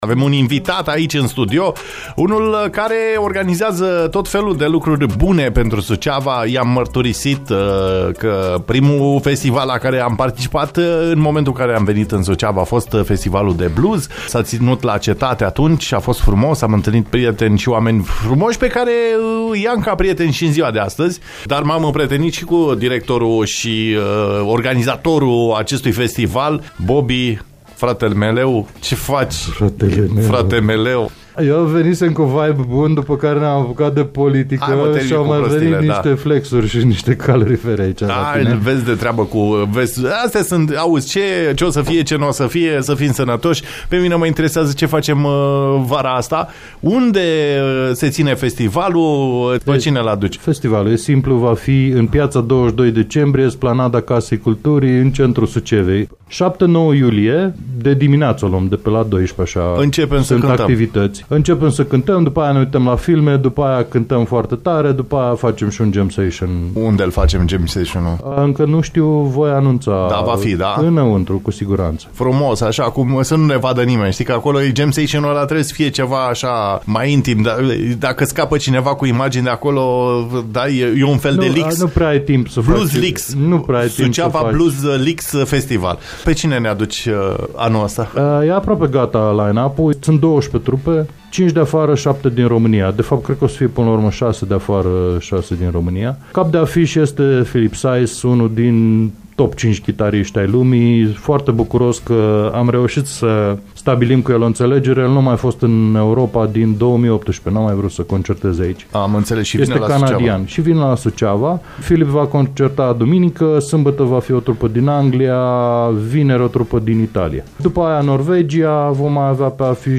Invitat astăzi la DIS DE DIMINEAȚĂ